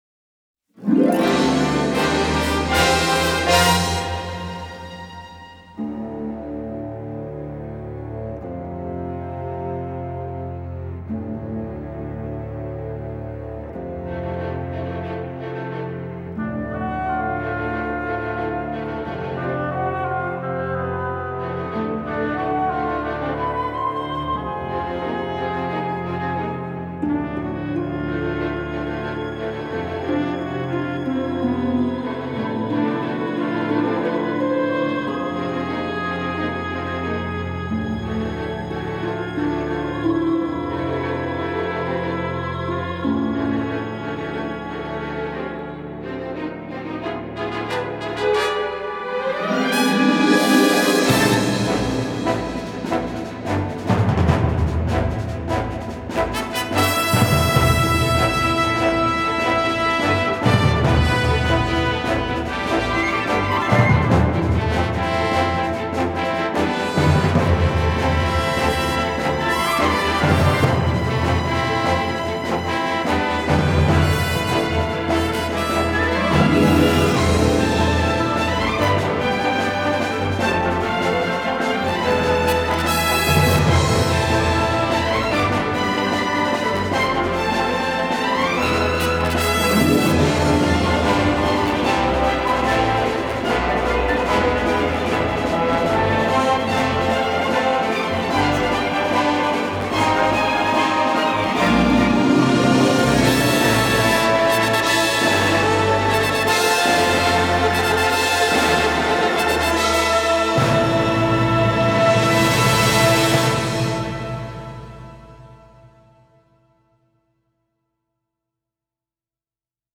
Ouah l'orchestral ça en jette !